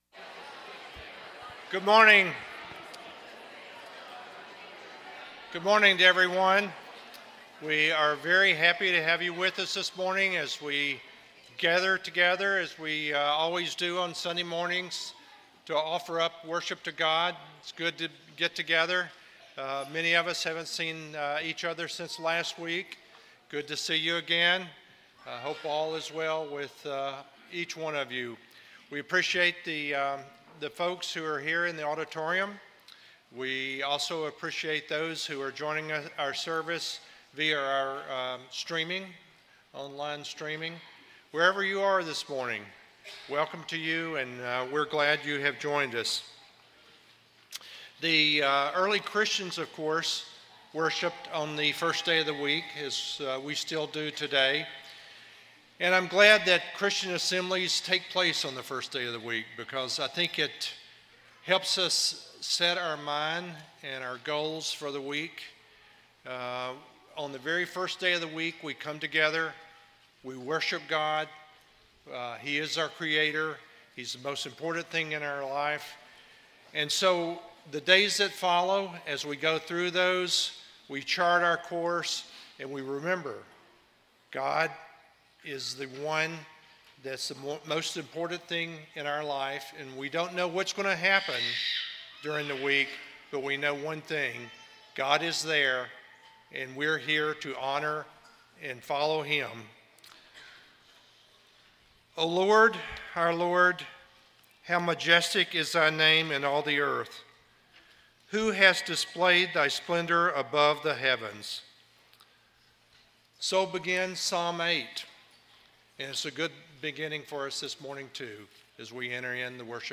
John 1:29, English Standard Version Series: Sunday AM Service